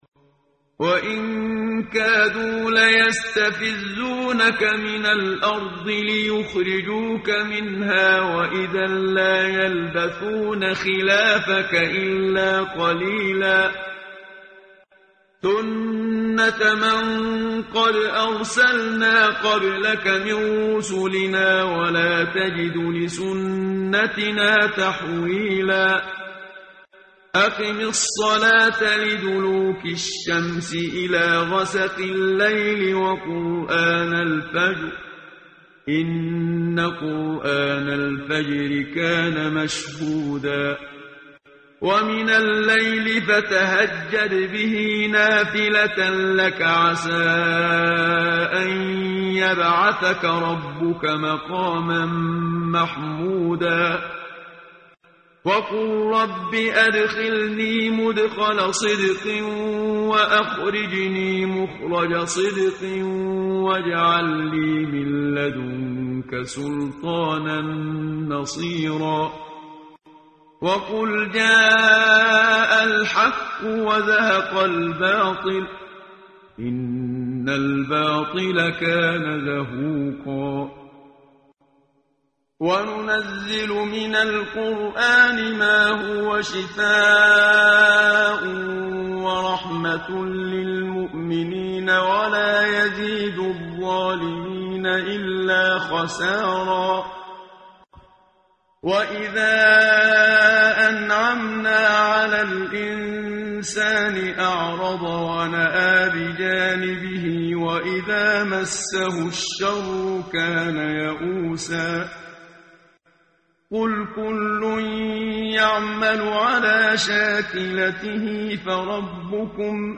قرائت قرآن کریم ، صفحه 289 ، سوره مبارکه الاسراء آیه 59 تا 66 با صدای استاد صدیق منشاوی.